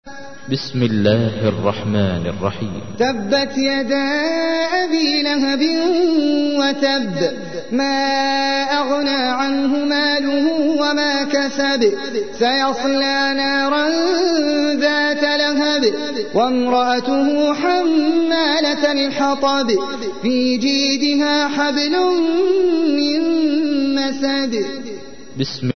تحميل : 111. سورة المسد / القارئ احمد العجمي / القرآن الكريم / موقع يا حسين